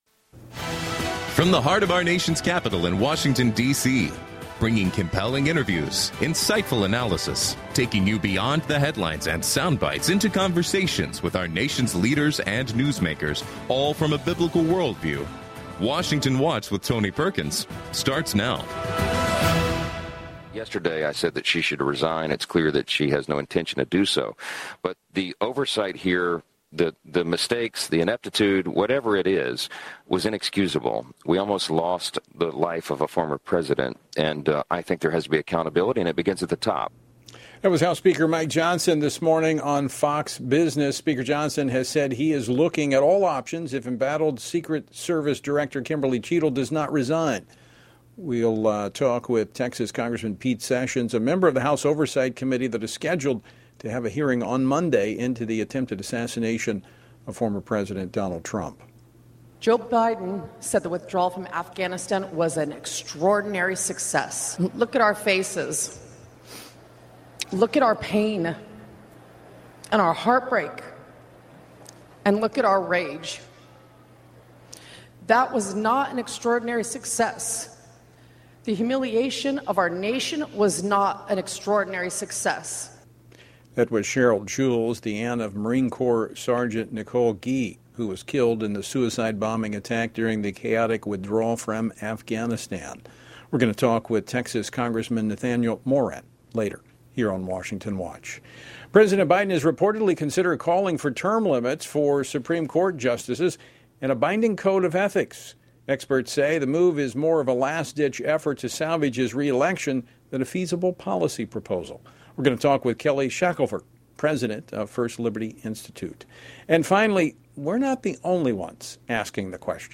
On today’s program: Pete Sessions, U.S. Representative for the 17th District of Texas, provides an update on the House Oversight & Accountability Committee’s investigation into the U.S. Secret Service following the assassination attempt on former President Donald Trump. Nathaniel Moran, U.S. Representative for the 1st District of Texas, discusses the final day of the Republican National Convention and highlights his discussions with a European Union delegation regarding threats from the Chinese Communist Party.
Robert George , McCormick Professor of Jurisprudence at Princeton University, unpacks how Christians should respond to the GOP 2024 Platform , which backs away from long-standing Republican principles.